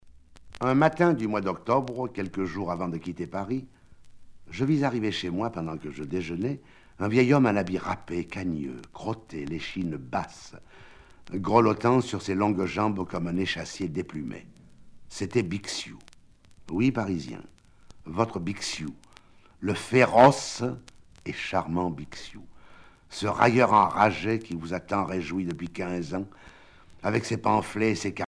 Catégorie Récit